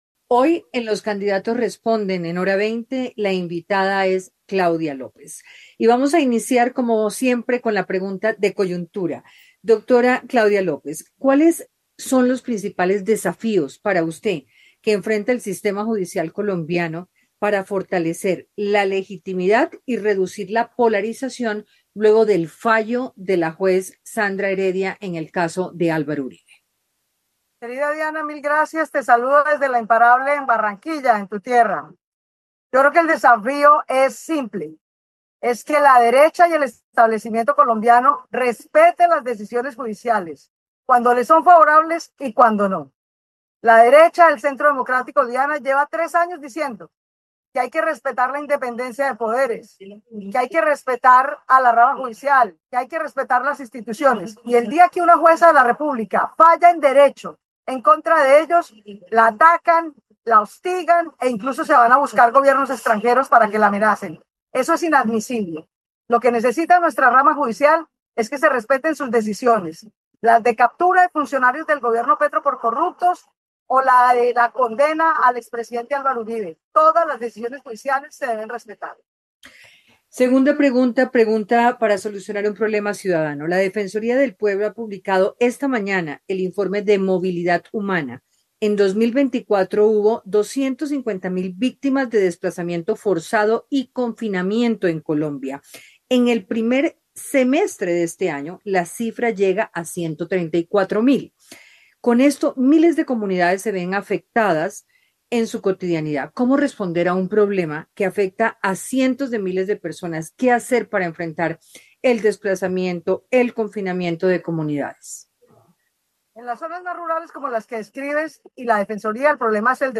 En diálogo con “Los candidatos responden: escuche y decida”, la precandidata presidencial Claudia López habló desde Barranquilla de varios temas.